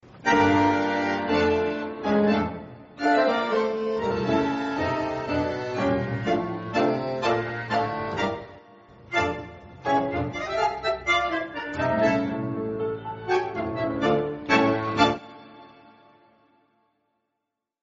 Klassik